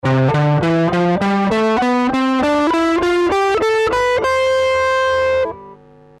The Major Scale
Listen (Slow Speed)
CMajorScale1.mp3